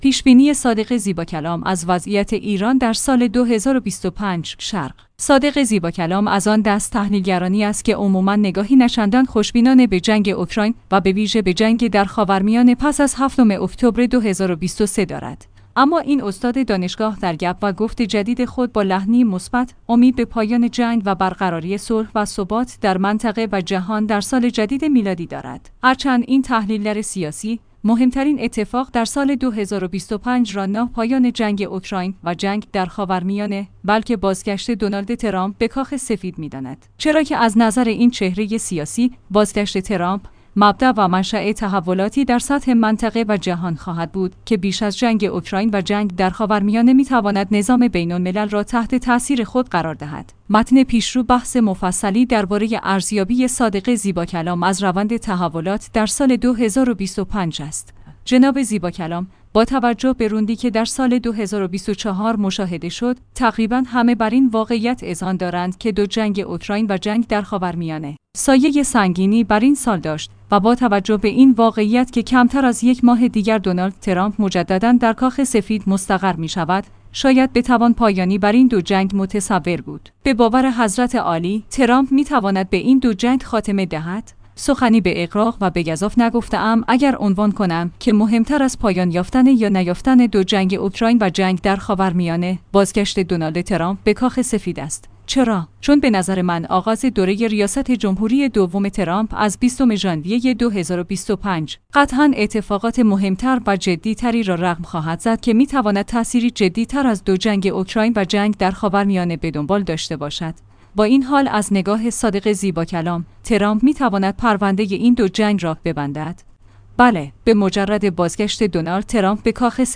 اما این استاد دانشگاه در گپ‌و‌گفت جدید خود با لحنی مثبت، امید به پایان جنگ و برقراری صلح و ثبات در منطقه و جهان در سال جدید میلادی دارد.